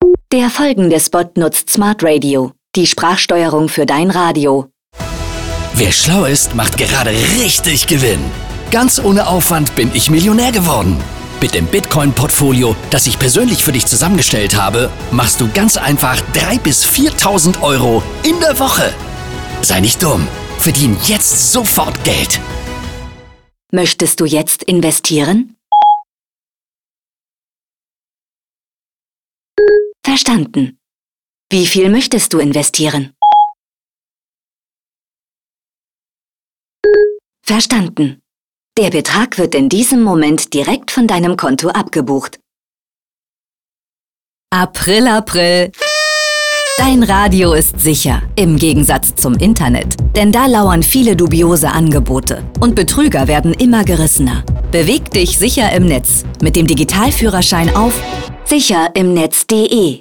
Dazu werden Hörer:innen von einem vermeintlich “smarten Radio” mit Spracherkennung, im Stile von Amazons Alexa oder Apples Siri reingelegt, um sie in den Bereichen Onlinebanking, Dating und Shopping auf Risiken im Internet aufmerksam zu machen.